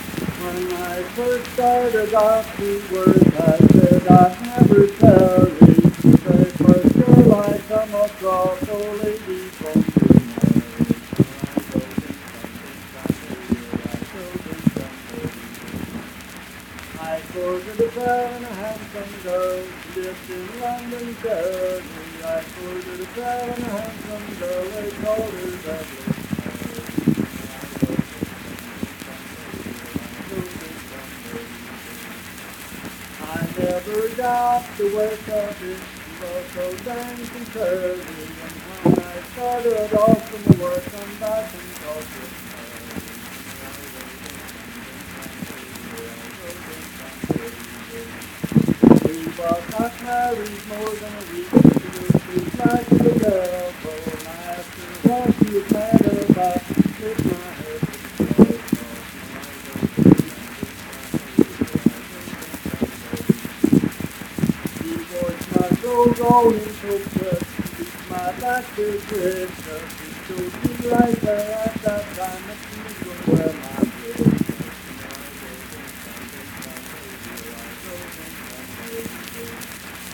Unaccompanied vocal music performance
Verse-refrain 5(6w/R).
Voice (sung)